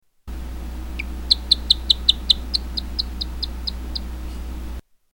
Gecko sound
Tags: Travel Croatia Sounds of Croatia Destination Zagreb Croatia